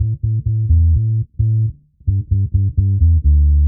Index of /musicradar/dub-designer-samples/130bpm/Bass
DD_PBass_130_A.wav